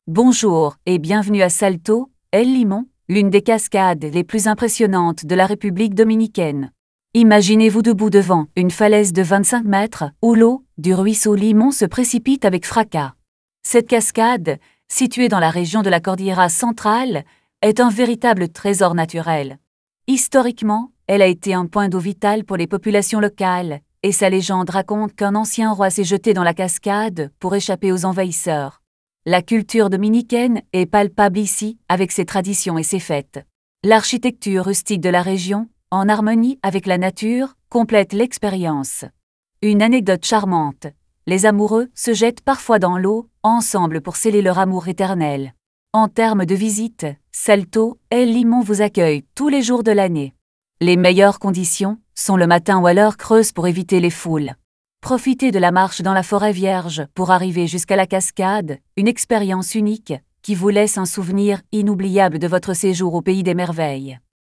karibeo_api / tts / cache / 8d0c821b5ef789046b4dad4e947a23d6.wav